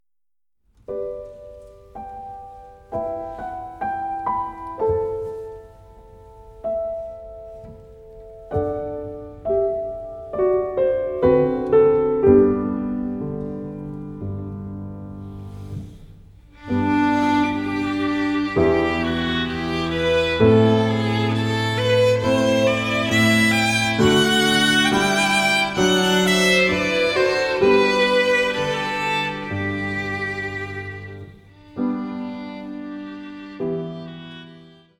Streicher, Klavier